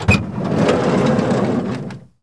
PIANETA GRATIS - Audio/Suonerie - Mezzi di Trasporto - Auto 15
SLIDE DOOR O.WAV